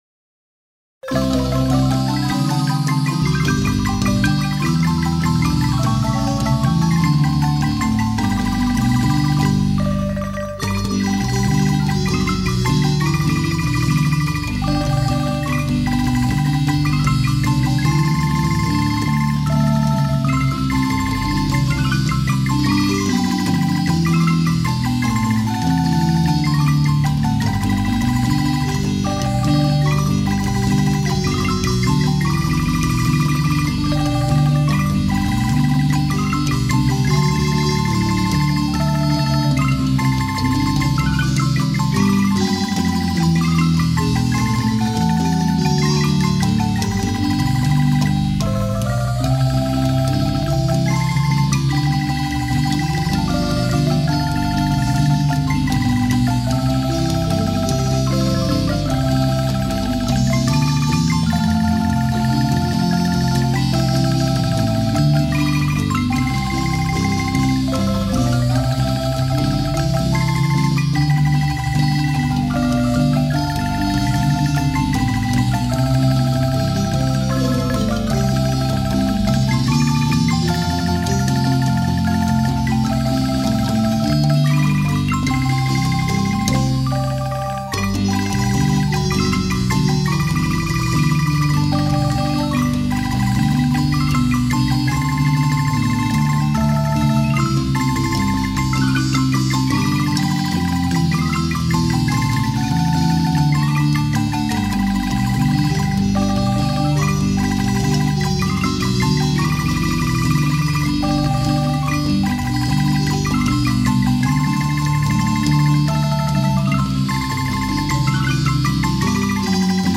Las obras, ordenadas de 1 a 8, fueron grabadas en estudio durante el año 2006 con la marimba escuadra (marimba grande y un tenor) de la Casa de la Cultura de Santa Cruz, Guanacaste.
MUSICA, GUANACASTE, MARIMBA